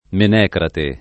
[ men $ krate ]